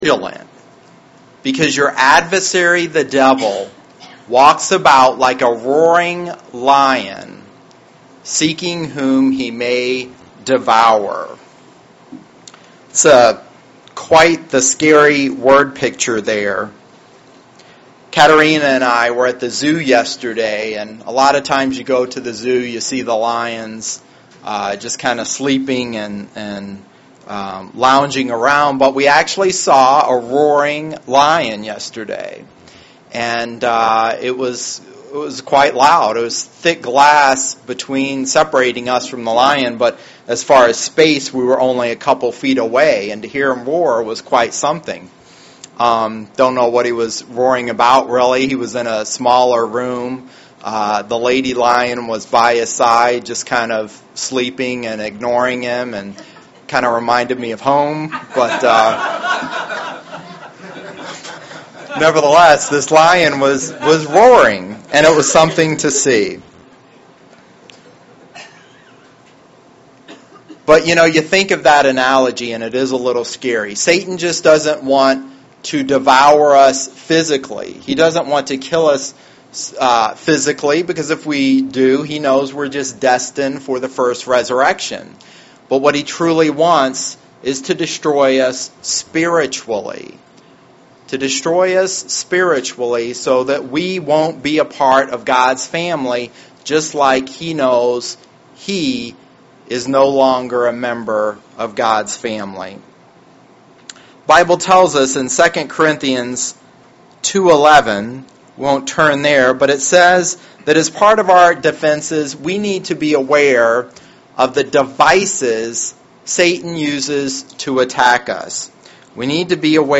Sermons
Given in Lansing, MI